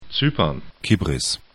'tsy:pɐn